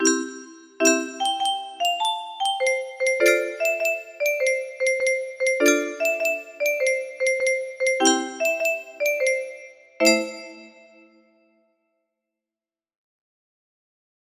M1-M3 music box melody